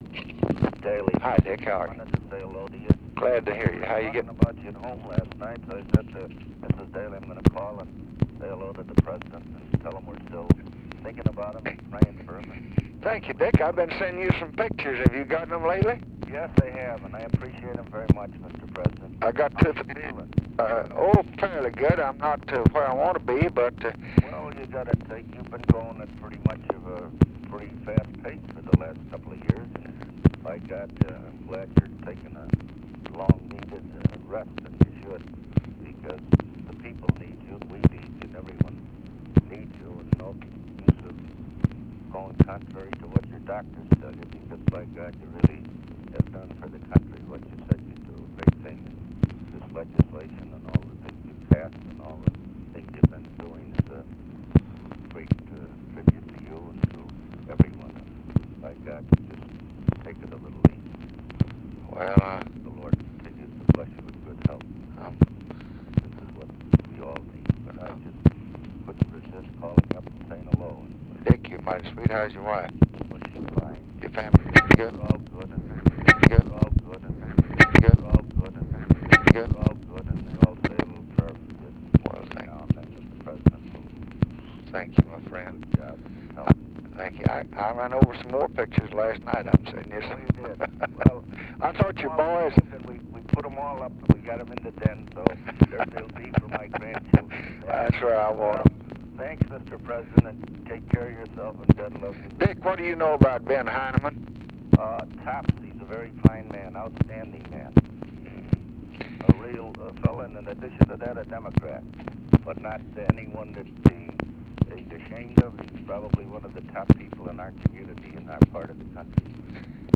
Conversation with RICHARD DALEY, December 1, 1965
Secret White House Tapes